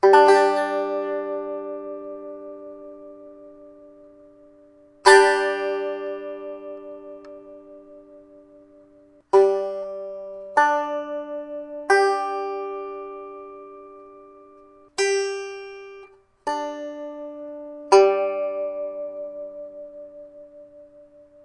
弦乐棒开放品格的弦乐 拨动
描述：Strumstick 3 Strings CGC open fret strum pluck
Tag: 沥青 乱弹 strumstick 乐器 吉他 strumstick 拔毛 笔记 样品